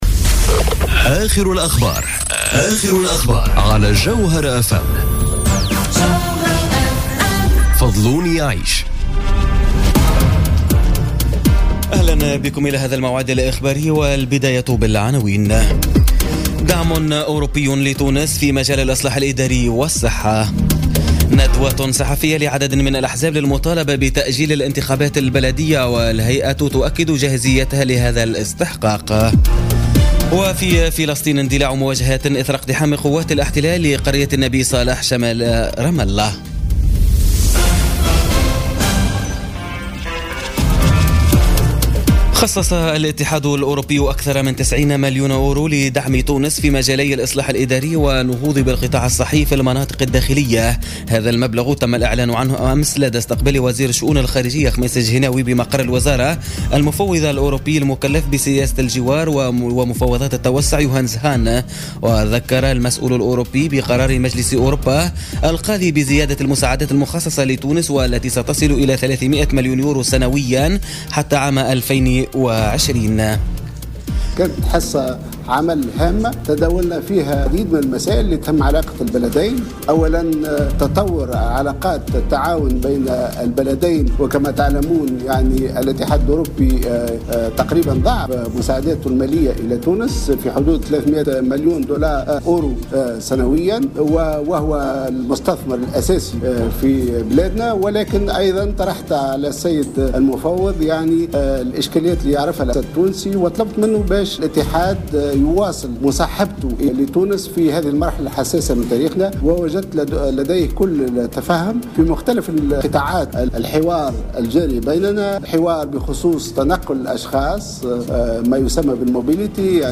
نشرة أخبار منتصف الليل ليوم الثلاثاء 5 سبتمبر 2017